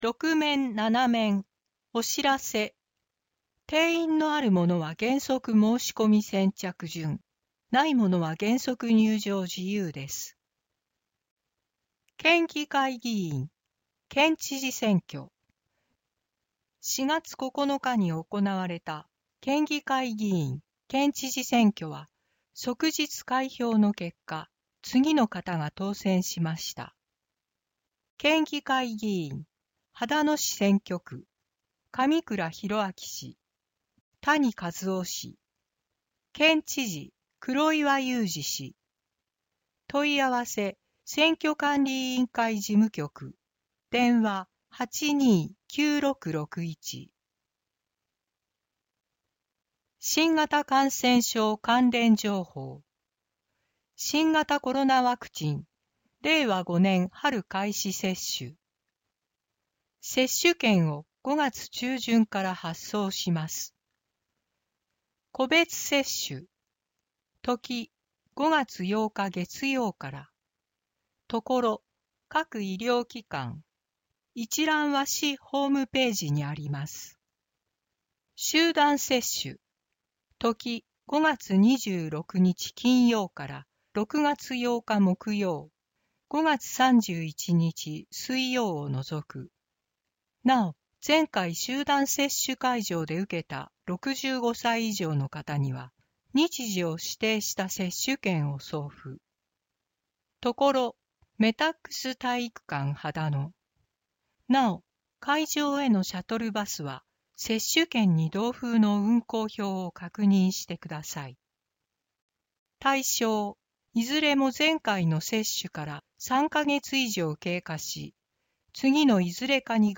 声の広報